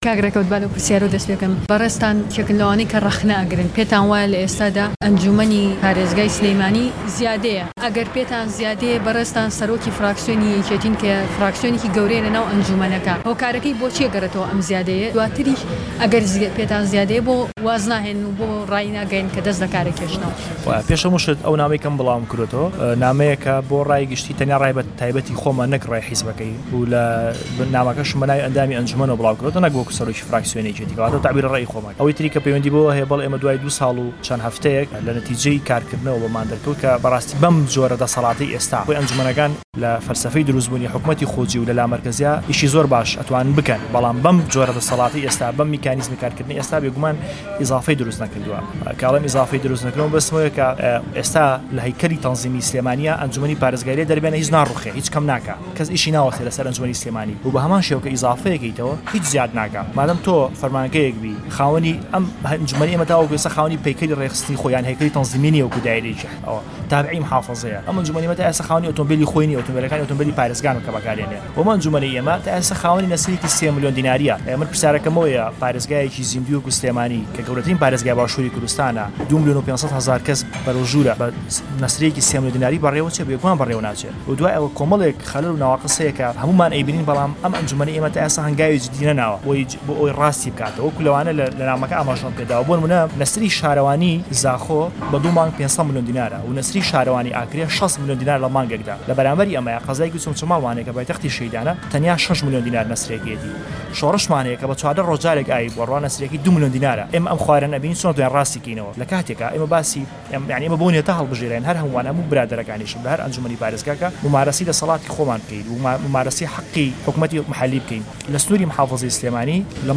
وتووێژ لەگەڵ ڕێکەوت زەکی